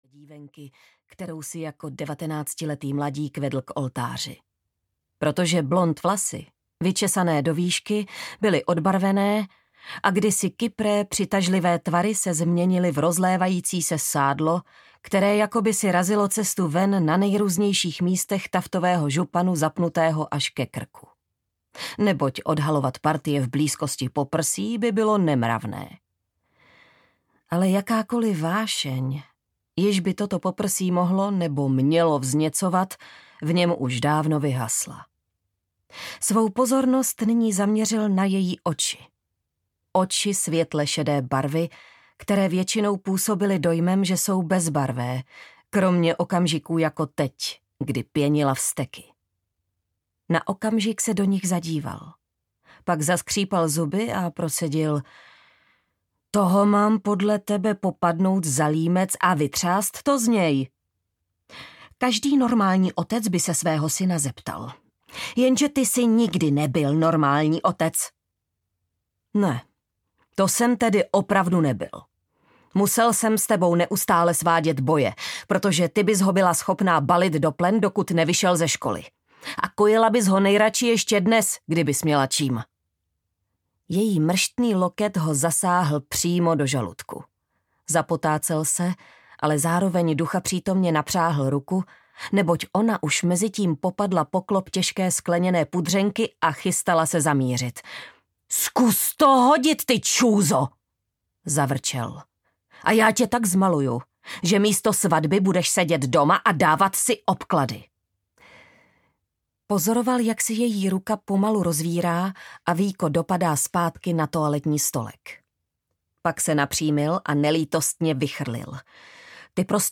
Rok neviňátek audiokniha
Ukázka z knihy